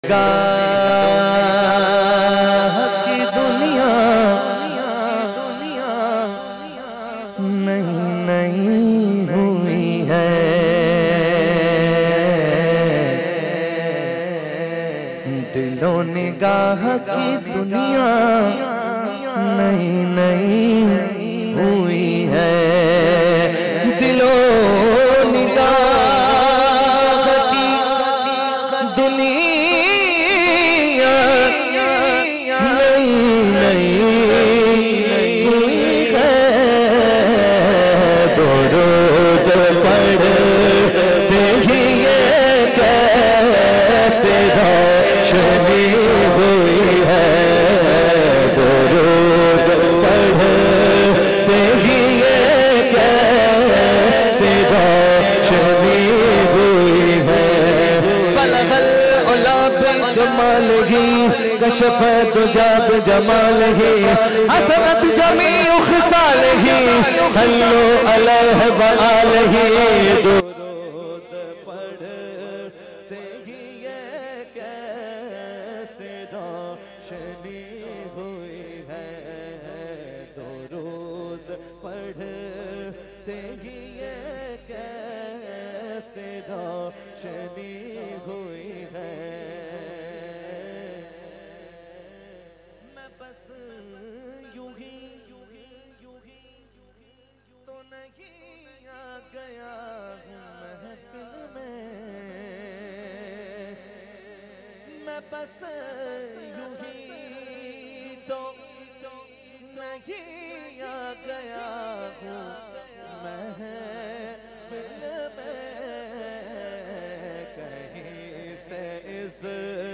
Beautiful Naat
in best audio quality